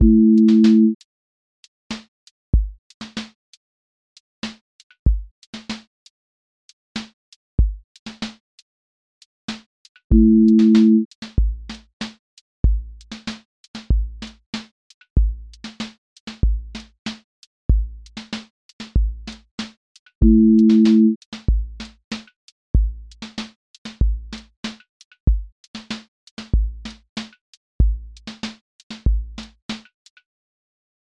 QA Listening Test r&b Template: rnb_pocket
• voice_kick_808
• voice_snare_boom_bap
• voice_hat_rimshot
• voice_sub_pulse
Smooth r&b pocket with warm chord bed and supportive bass